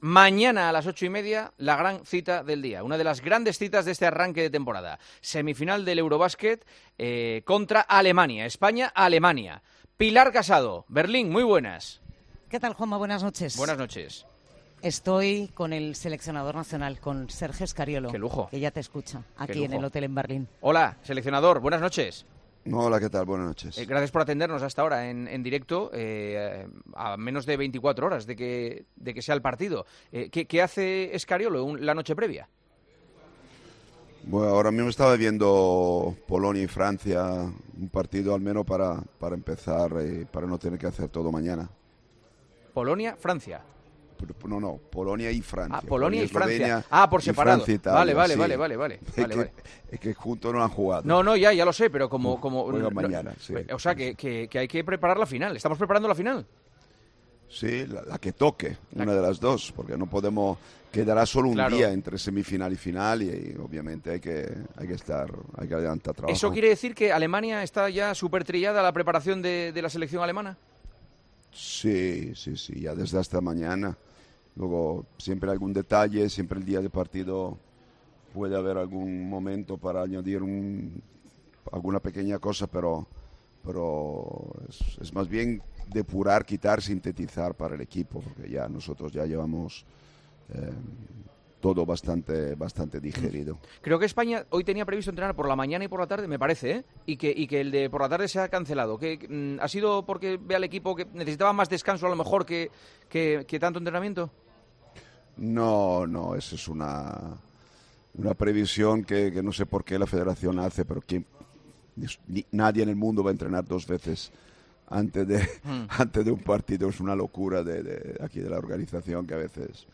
El seleccionador español de baloncesto ha pasado por los micrófonos de El Partidazo de COPE en la previa de las semifinales del Eurobasket, en las que...